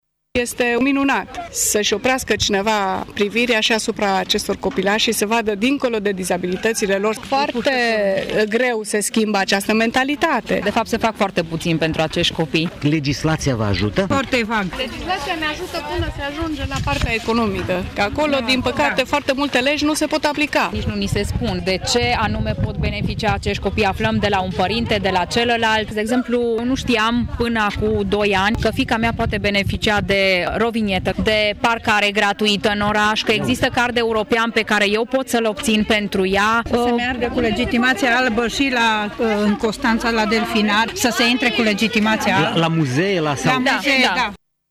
Părinții copiilor se bucură că evenimentul a fost organizat chiar la Tîrgu-Mureș, deși în societatea românească persistă unele mentalități depășite în privința persoanelor cu dizabilități: